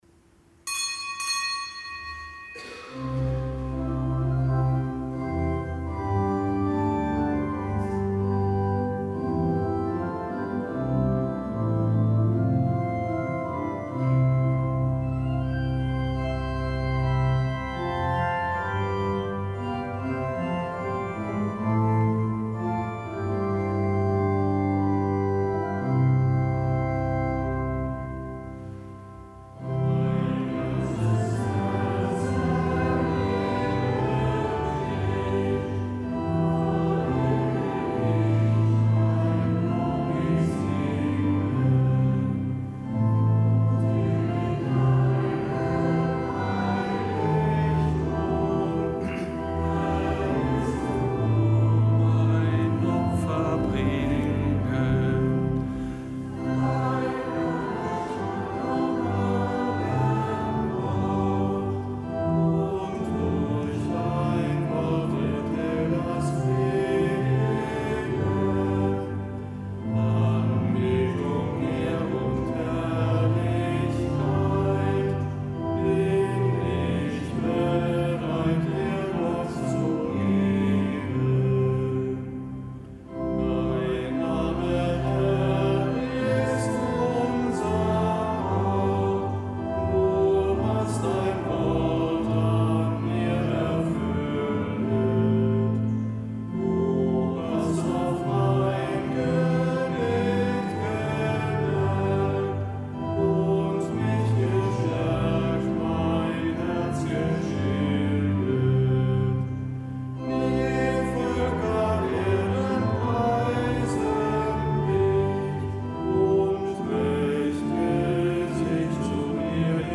Kapitelsmesse aus dem Kölner Dom am Gedenktag des Heiligen Johannes Chrysostomus, Bischof von Konstantinopel, Kirchenlehrer.